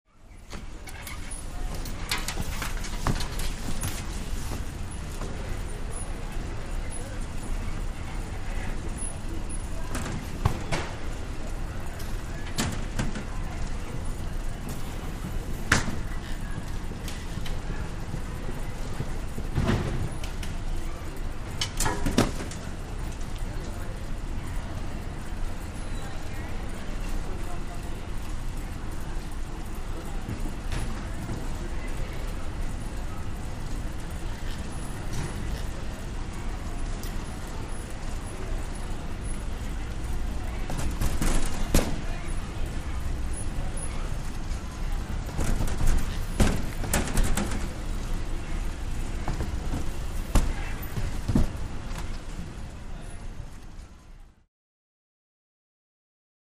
Baggage Claim Area, Belt Motion, Luggage Impacts And Mixed Walla, Close Point of View.